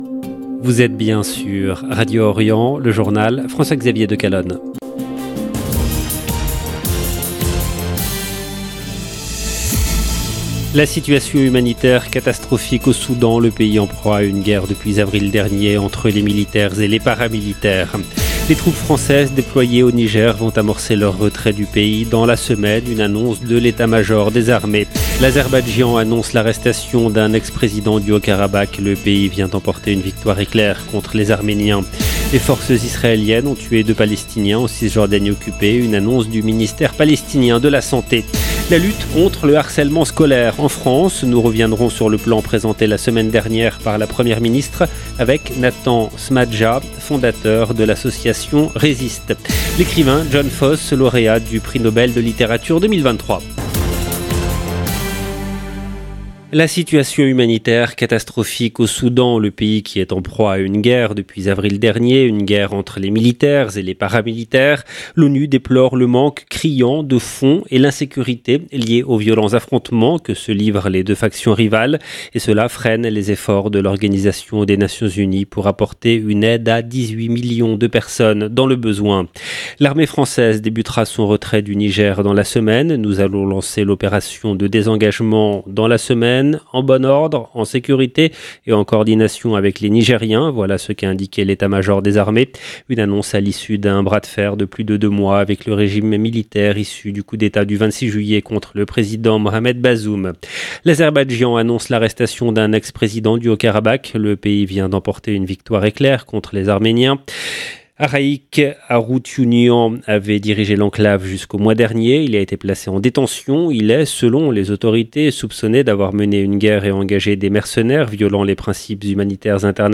LE JOURNAL EN LANGUE FRANÇAISE DU SOIR DU 5/10/23